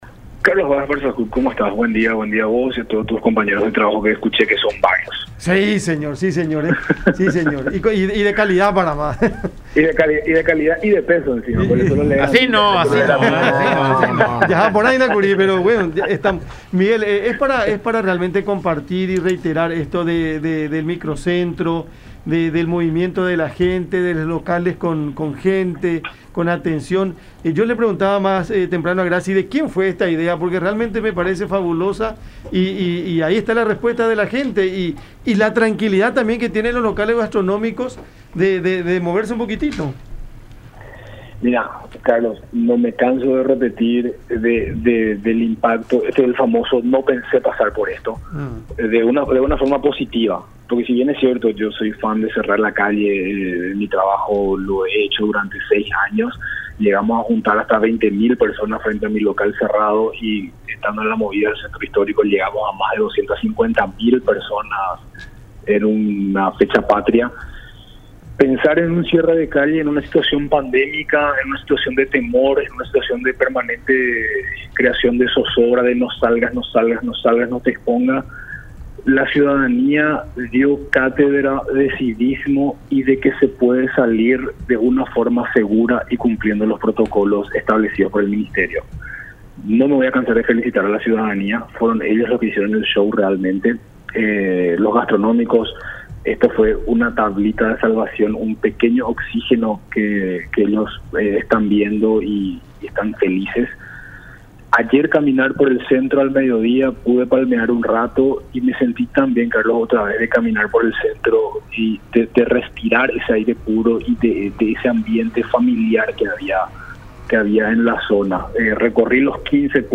en contacto con el programa Cada Mañana a través de La Unión